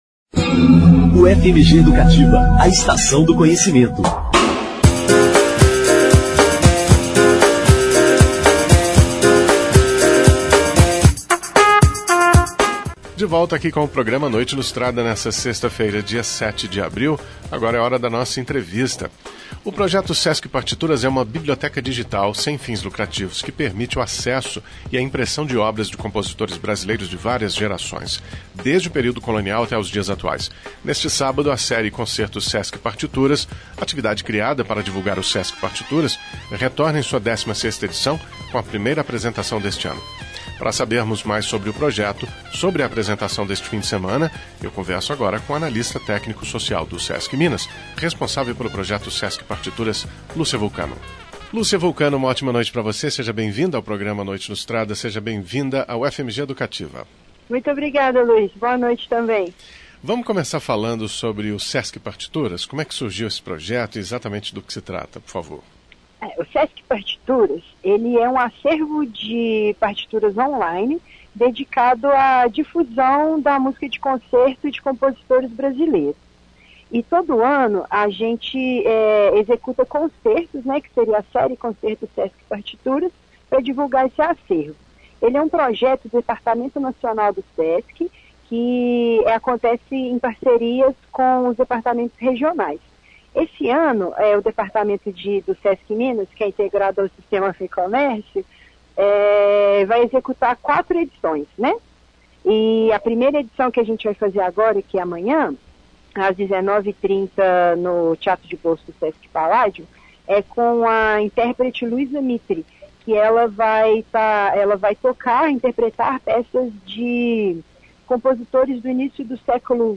A entrevista foi ao ar em 07/04/2017 no programa noite Ilustrada.